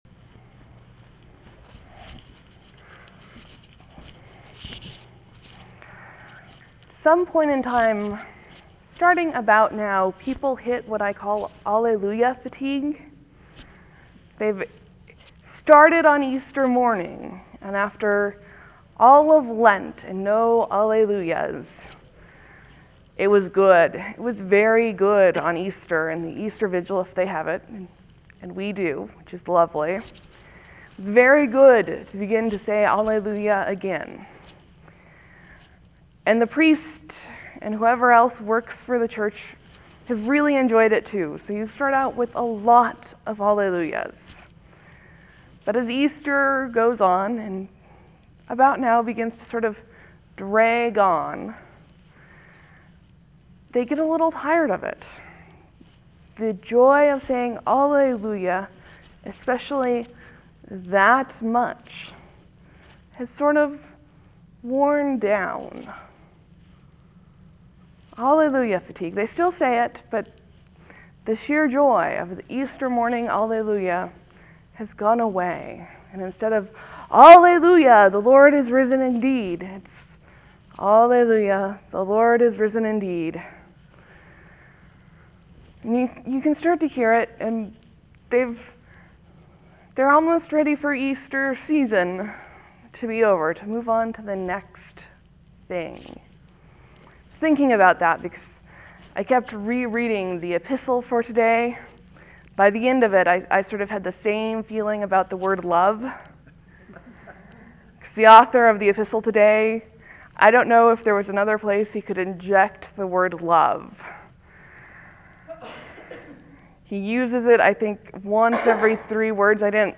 Easter, Sermon, , Leave a comment
(There will be a few moments of silence before the sermon starts. Thank you for your patience.)